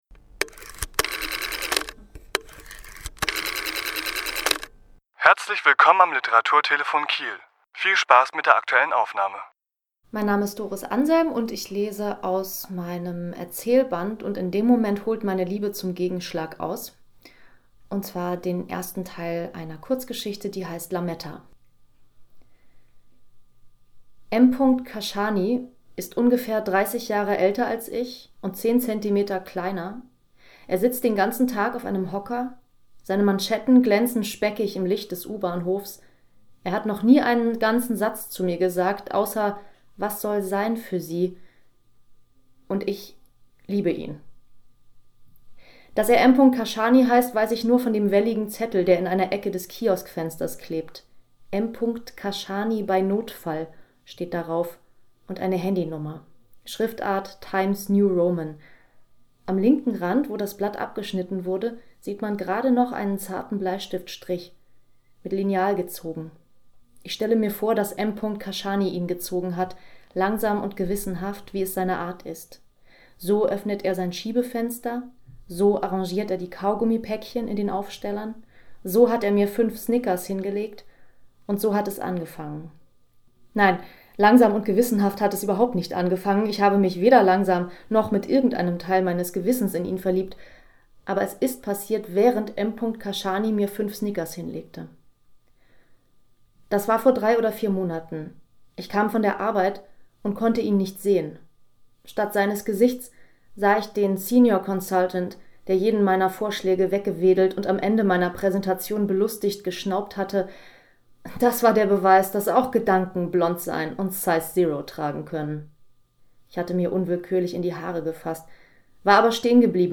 Autor*innen lesen aus ihren Werken
Die Aufnahme entstand im Rahmen einer Lesung im Literaturhaus Schleswig-Holstein in der Reihe „Junges Literaturhaus“ am 6.12.2017.